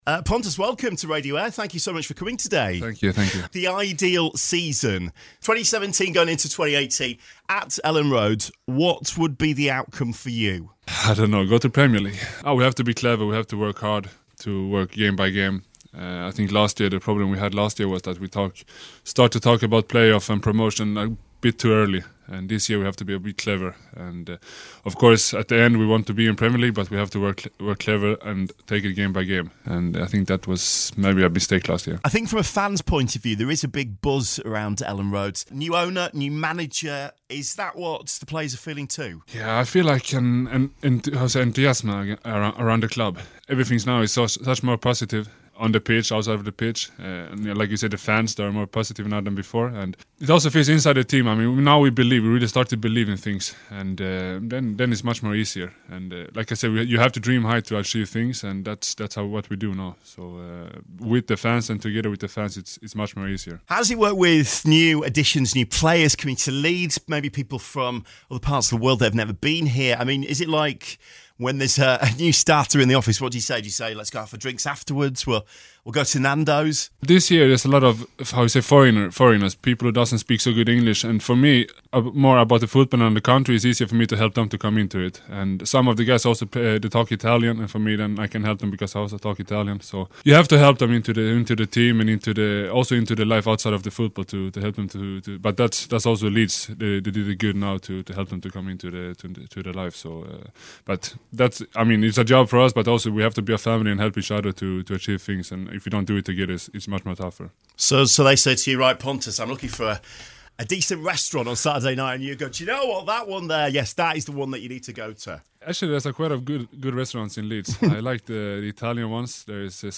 Pontus Jansson speaks exclusively to Radio Aire